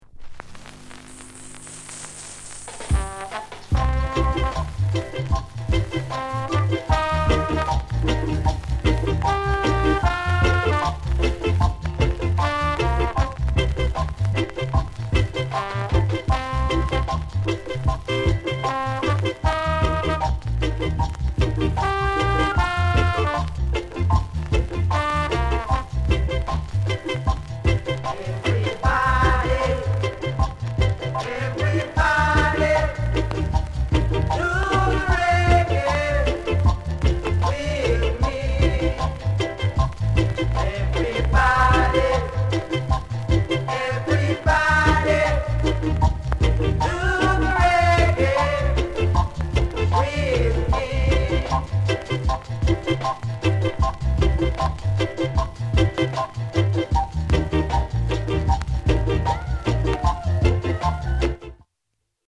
NICE INST